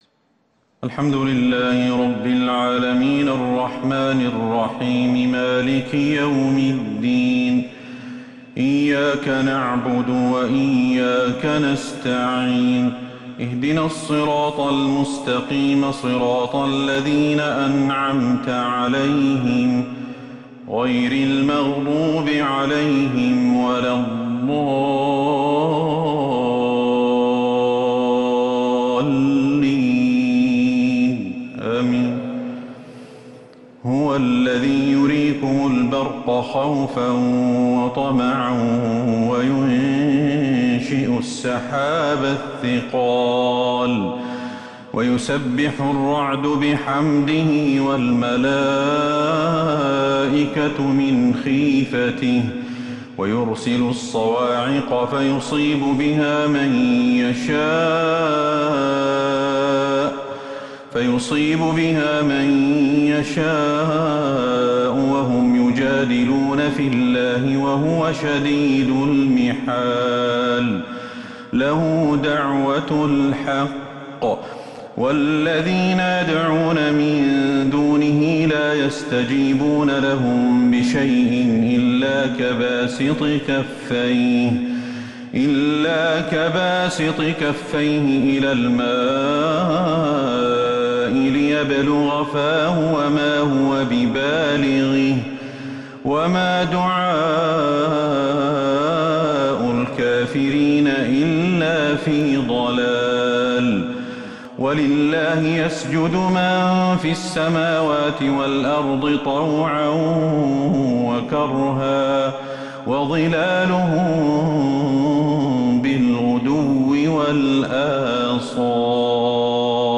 تراويح ليلة 17 رمضان 1442هـ آواخر سورة الرعد وفواتح سورة إبراهيم Taraweeh 17 th night Ramadan 1442H > تراويح الحرم النبوي عام 1442 🕌 > التراويح - تلاوات الحرمين